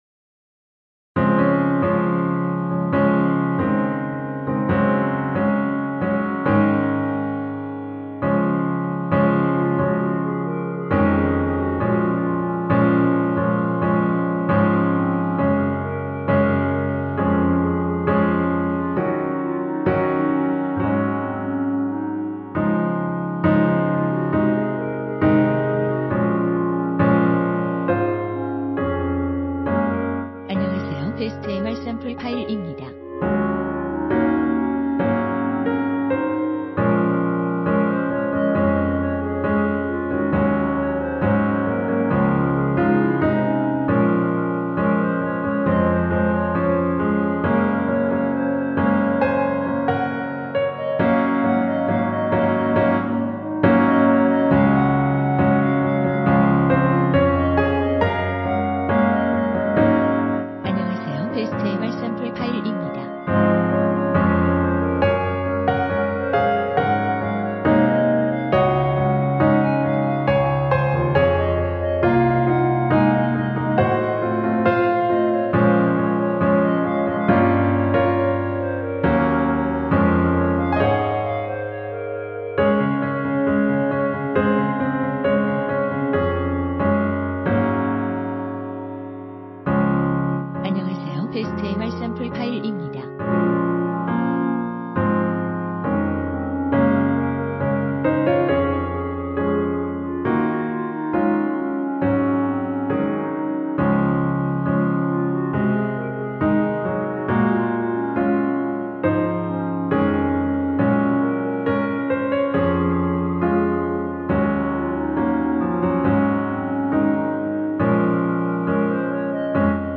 반주를 피아노 하나로 편곡하여 제작하였습니다.
원키(Piano Ver.)멜로디 MR입니다.
Db